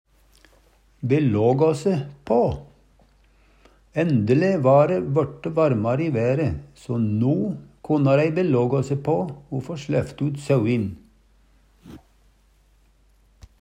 DIALEKTORD PÅ NORMERT NORSK belågå se på førebu seg Eksempel på bruk Ændle va re vøRte varmare i være, so no konna rei belågå se på o få slæpt ut sauin (på beite) Hør på dette ordet Ordklasse: Uttrykk Attende til søk